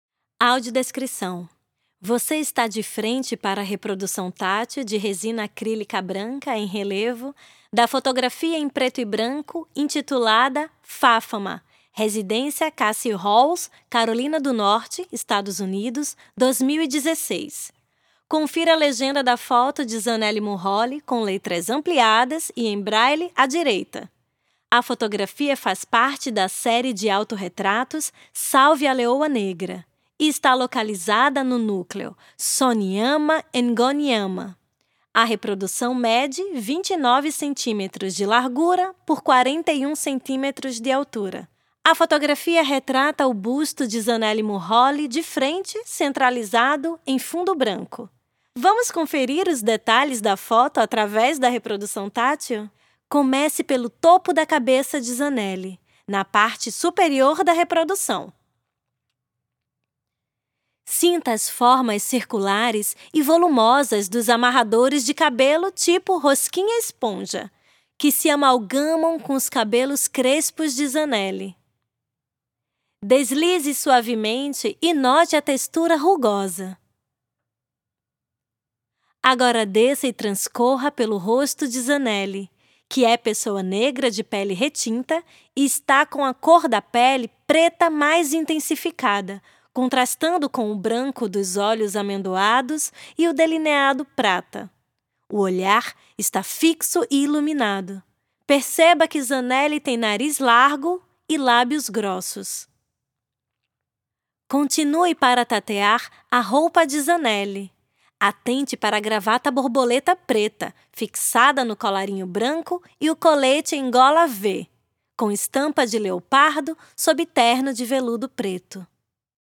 Audiodescrição - prancha tátil